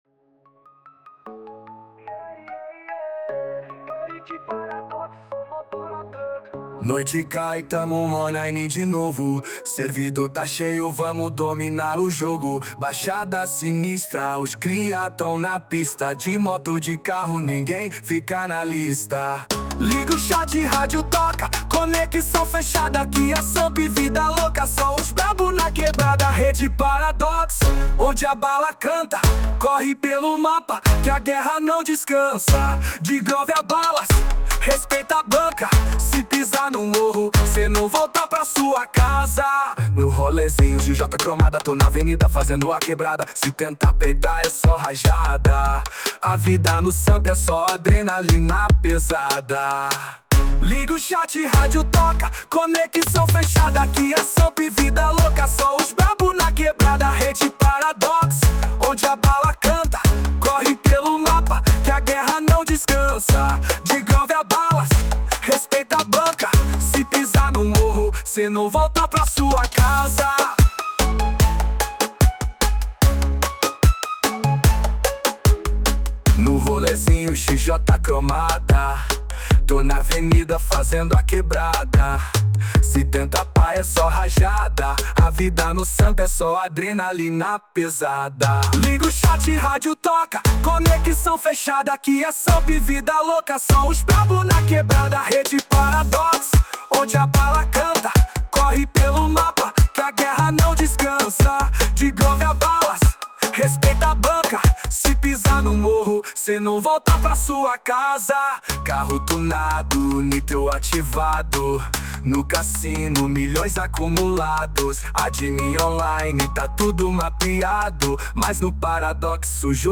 2025-04-03 12:26:12 Gênero: Trap Views